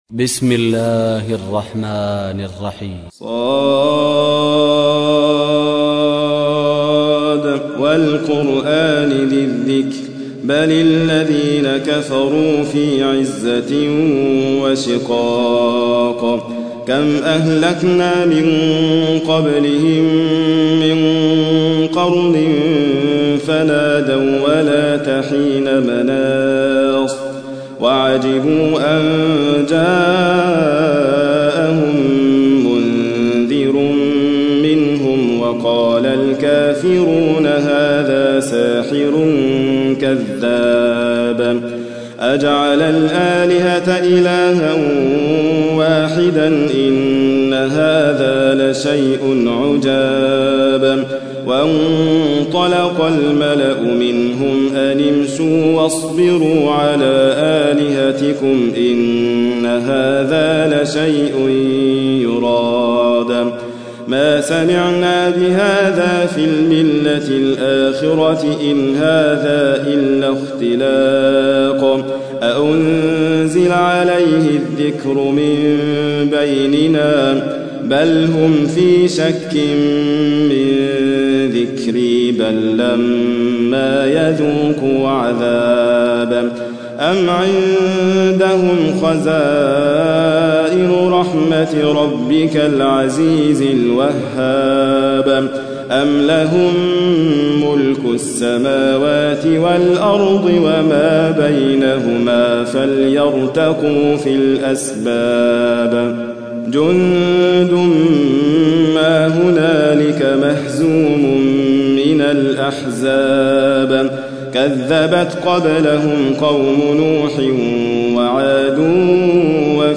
تحميل : 38. سورة ص / القارئ حاتم فريد الواعر / القرآن الكريم / موقع يا حسين